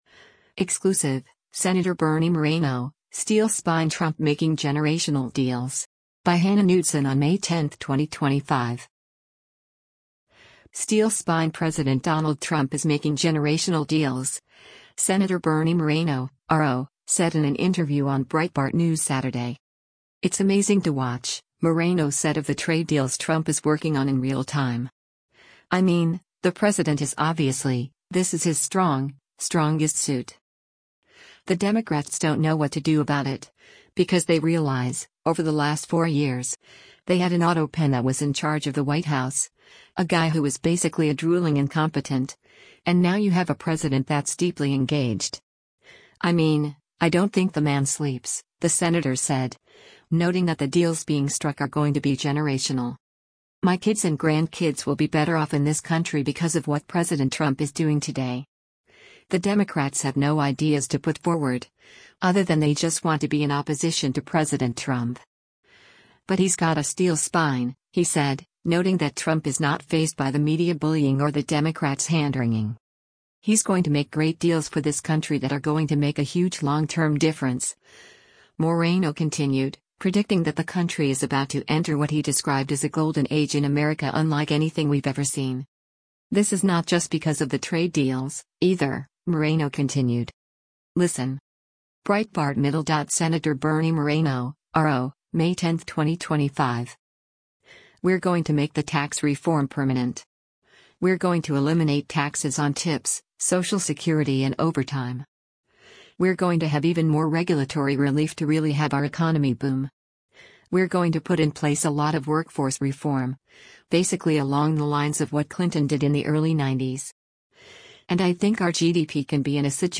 “Steel spine” President Donald Trump is making “generational” deals, Sen. Bernie Moreno (R-OH) said in an interview on Breitbart News Saturday.